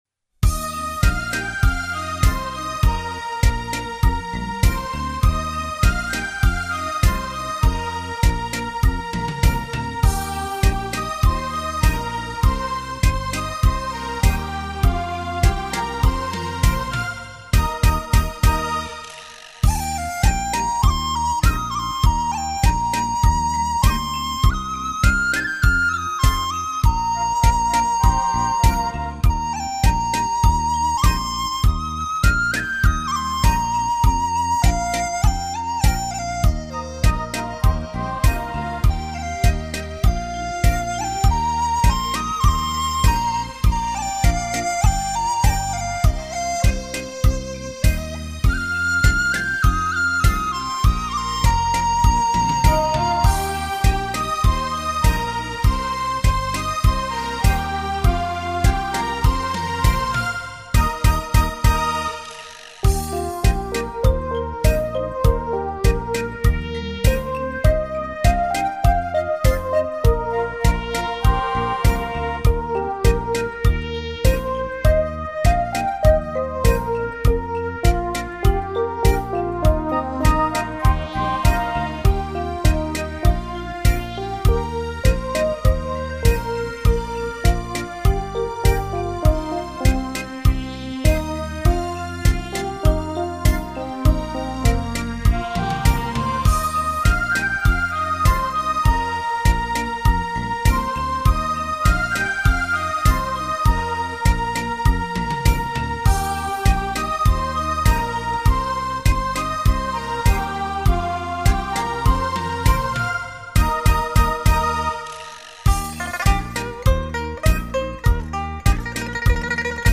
根据中国各地民歌精选改编的优美纯音乐舞曲 轻盈的舞曲旋律 动听的民族韵味!